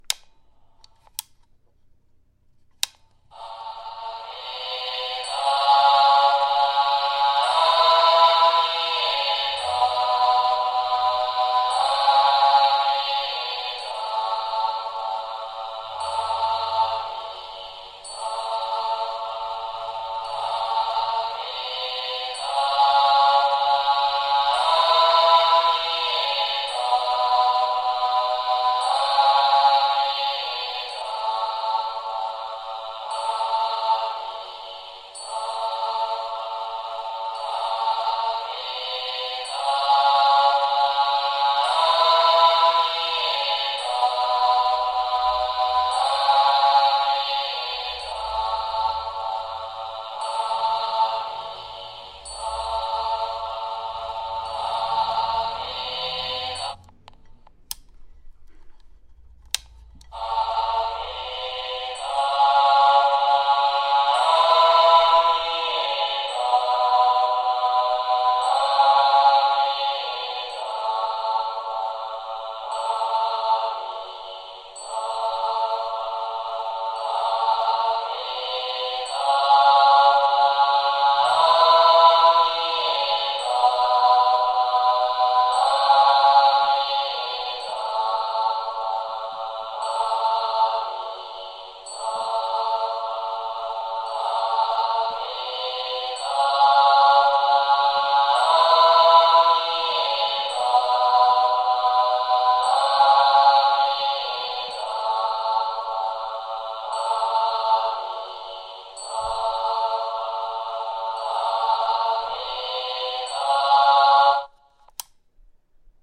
随机 " 佛教无人机音乐盒 立体声环境空间
描述：佛无人机音乐盒立体声环境roomy.flac
标签： 宽敞 环境 音乐 无人驾驶飞机
声道立体声